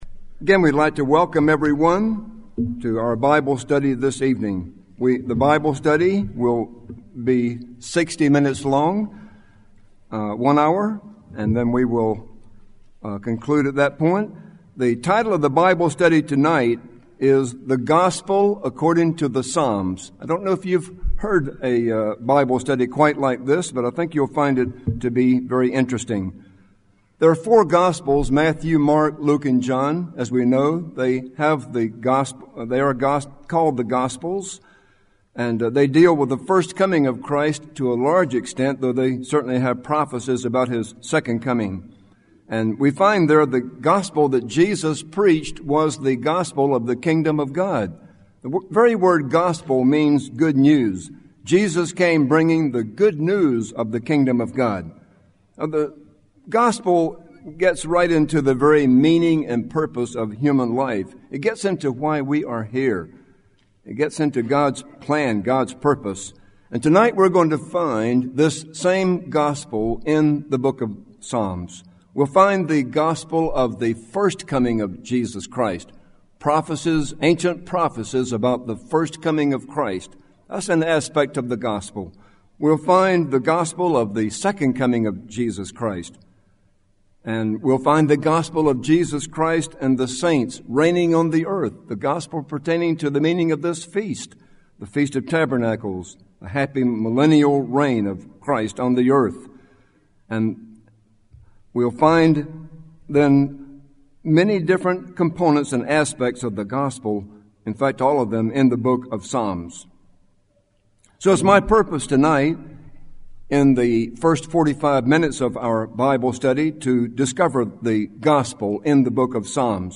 This sermon was given at the Jekyll Island, Georgia 2016 Feast site.